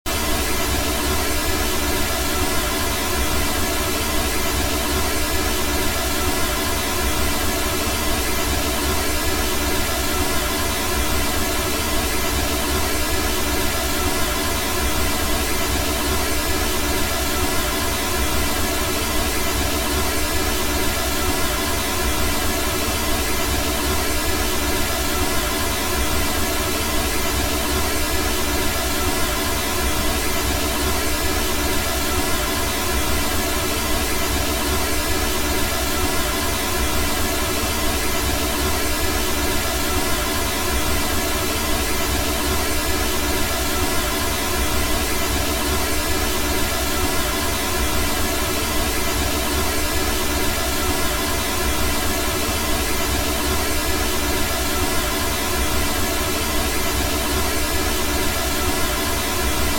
CombNoise_60sec.wav